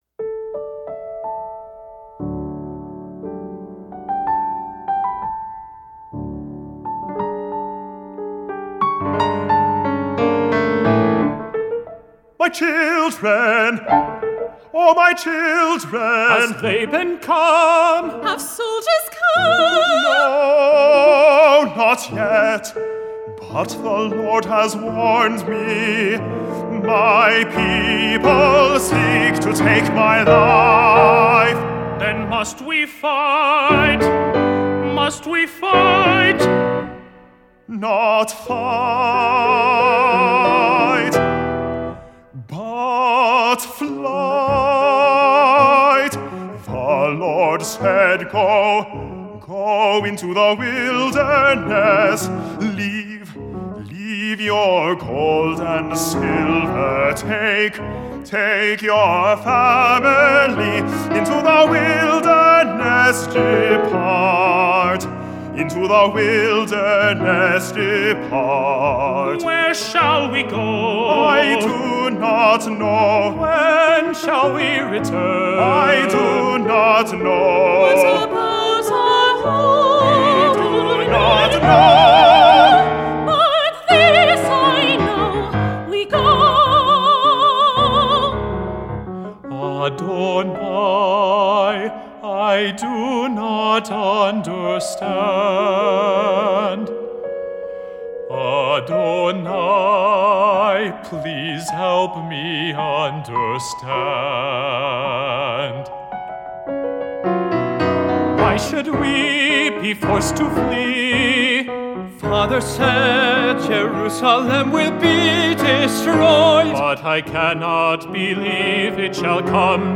Opera Chorus
Piano OR Fl, Ob, Cl, Bn, Hn, Tr, Perc, Hp, Pno, and Str